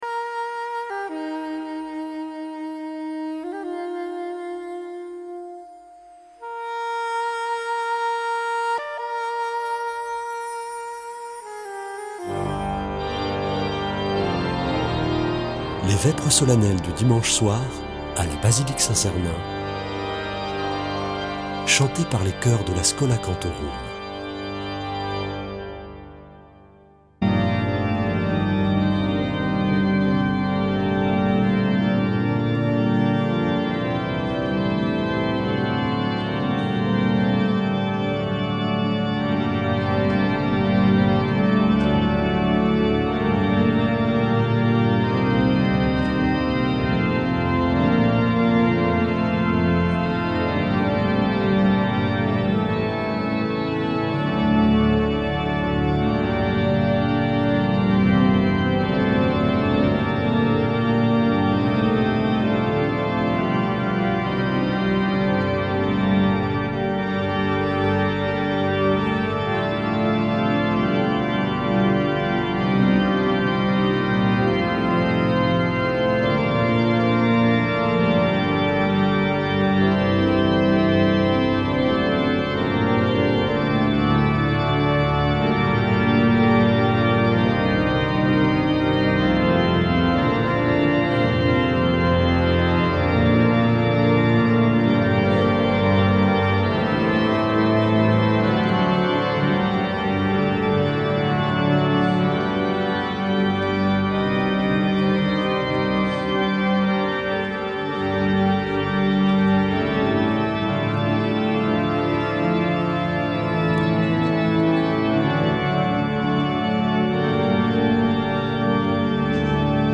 Vêpres de Saint Sernin du 26 nov.
Une émission présentée par Schola Saint Sernin Chanteurs